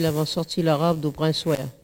collecte de locutions vernaculaires
Catégorie Locution